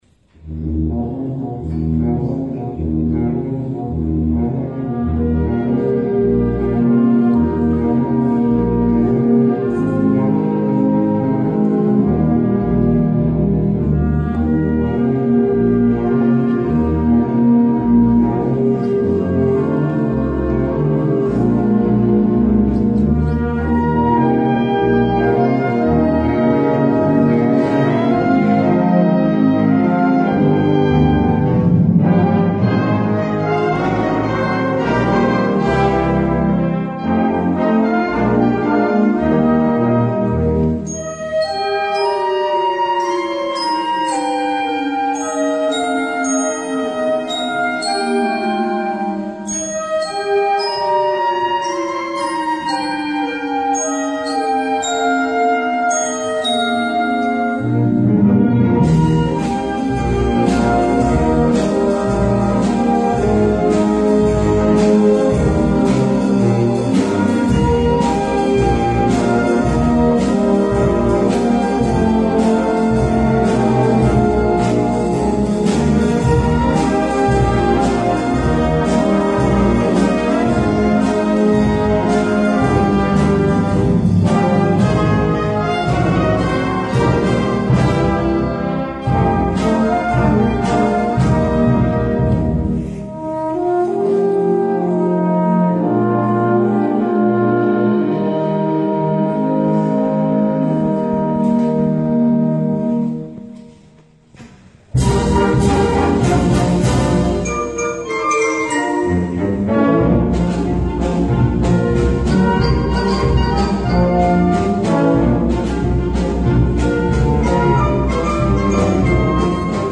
Per banda giovanile